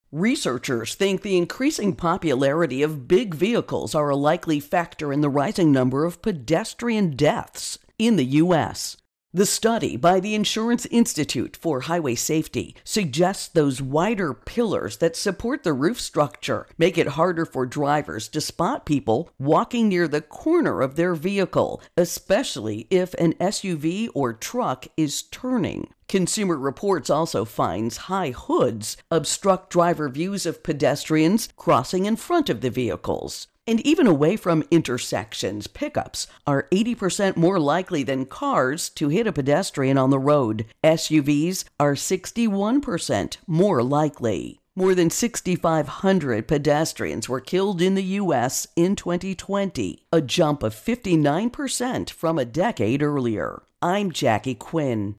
Trucks and SUVs Pedestrians Intro and Voicer